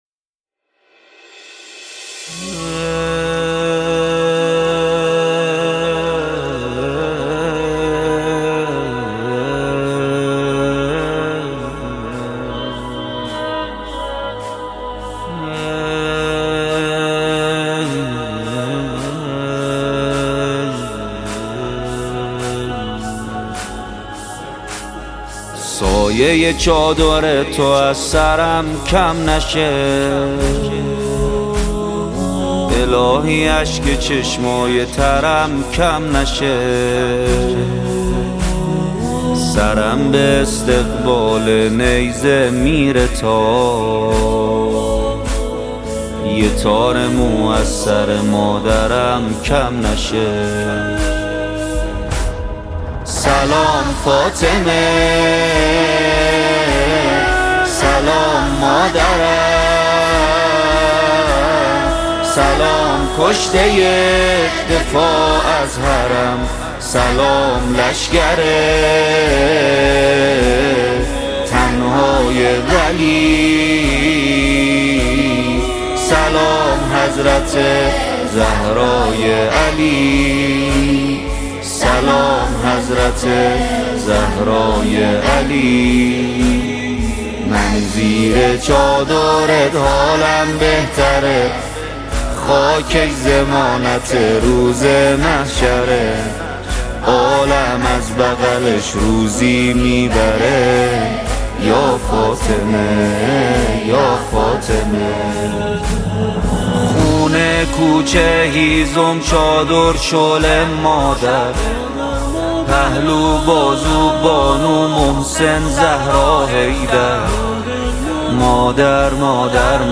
مداحی فاطمیه نوحه فاطمیه
مرثیه فاطمیه